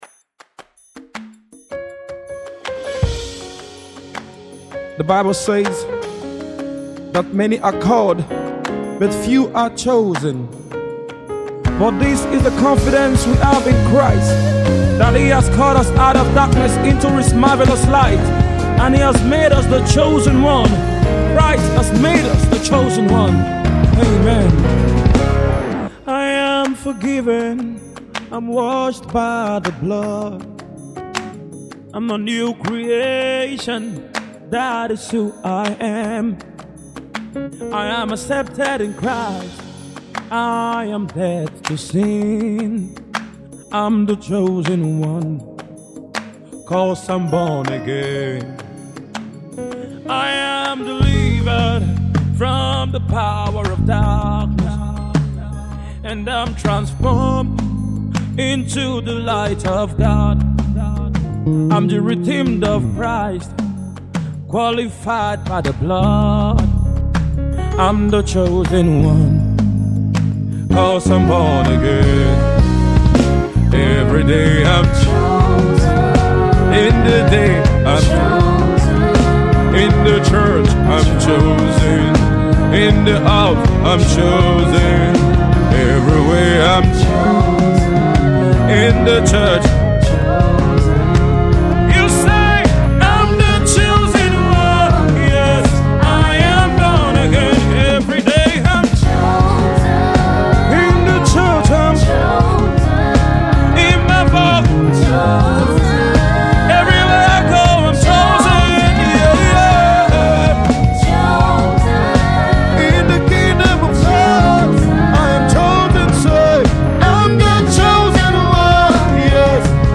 Tags:   Gospel Music,      Naija Music